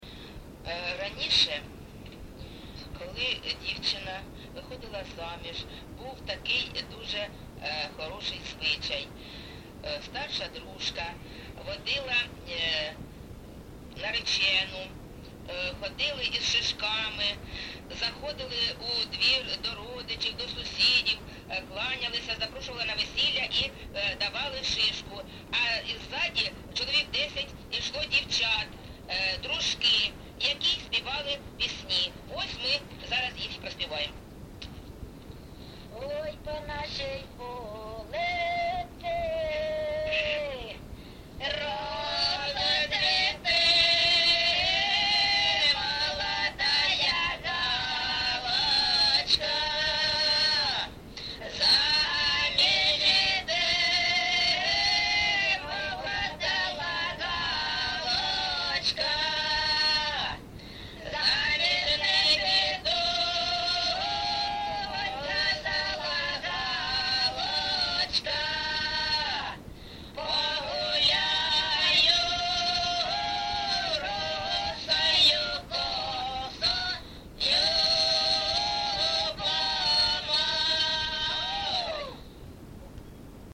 ЖанрВесільні
Місце записус. Некременне, Олександрівський (Краматорський) район, Донецька обл., Україна, Слобожанщина